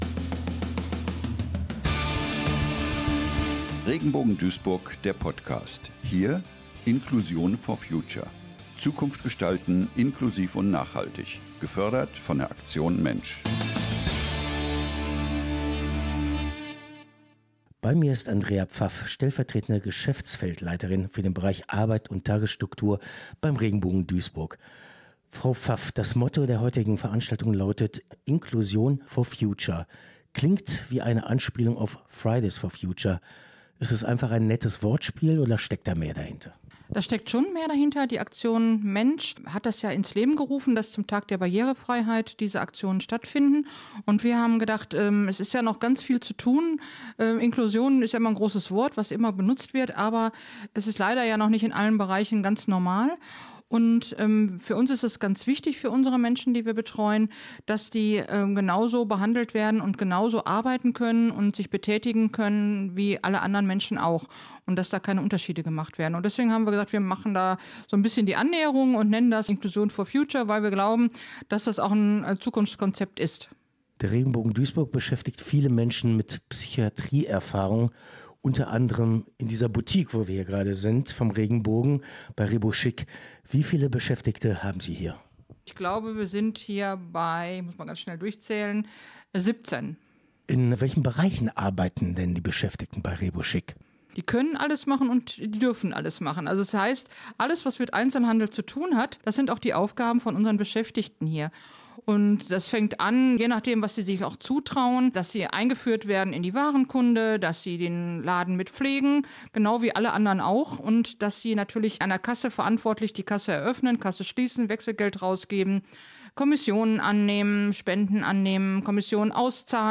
"Regenbogen Duisburg" erklärt im Interview, wie die Boutique mit